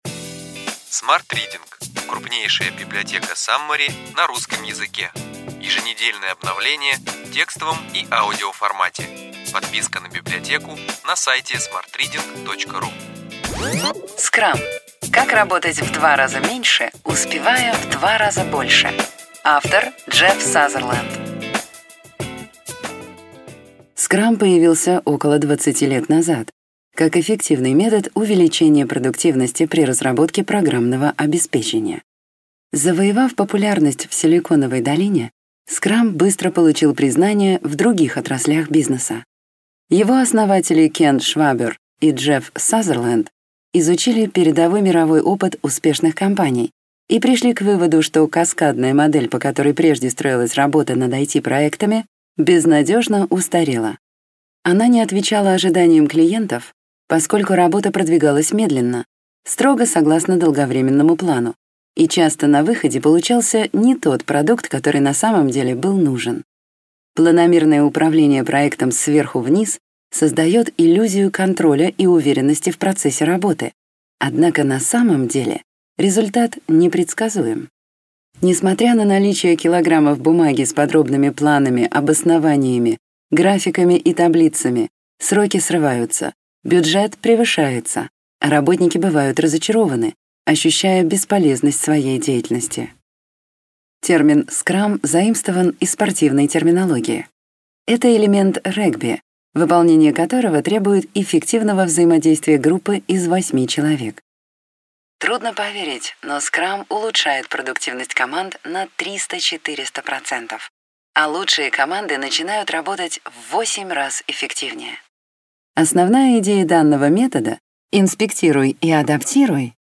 Аудиокнига Ключевые идеи книги: Scrum: как работать в два раза меньше, успевая в два раза больше.